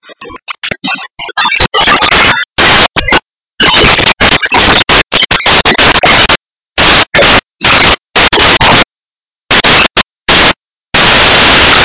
Tags: 60s